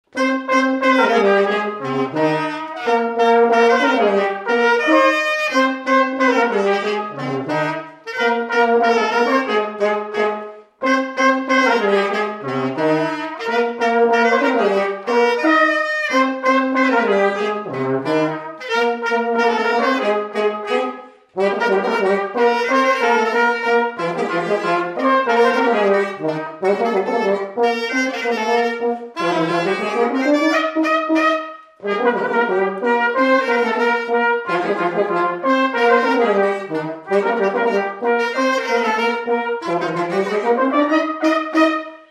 Polka
Chants brefs - A danser
Résumé instrumental
Pièce musicale inédite